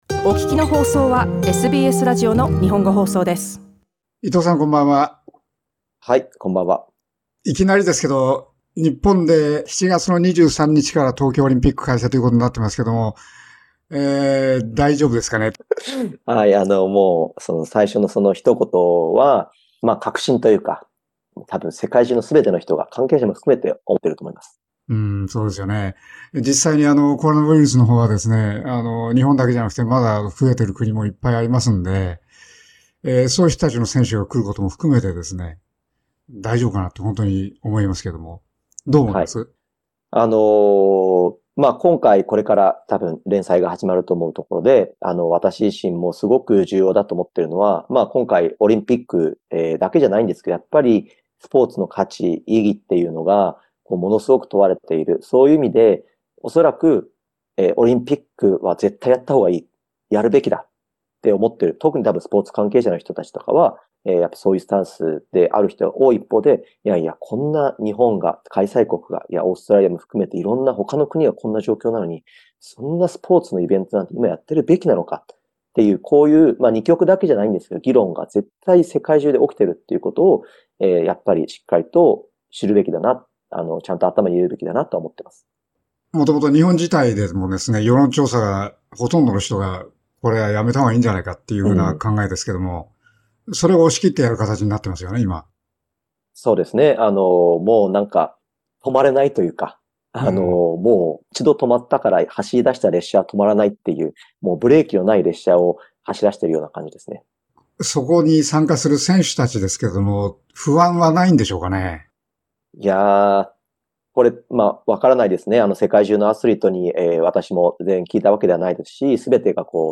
火木土の夜10時はおやすみ前にSBSの日本語ラジオ！